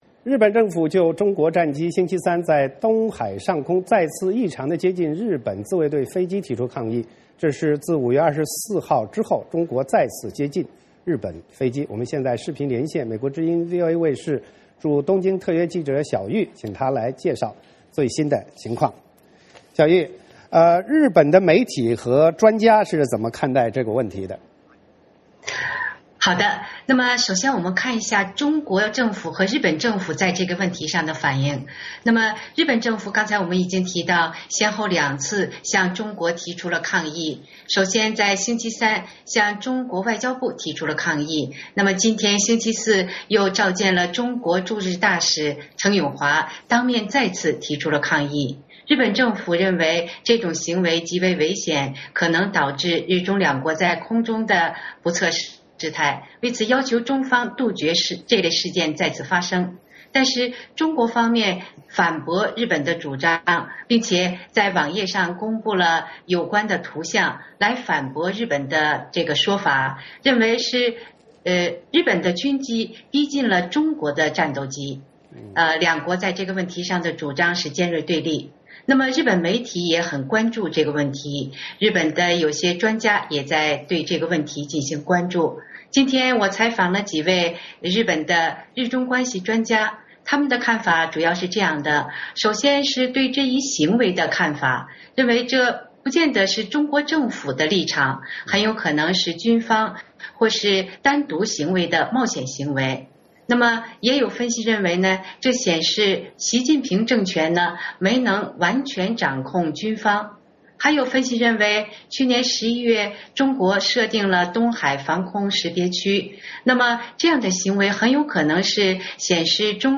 VOA连线：日媒反应：中国战机再接近日本战机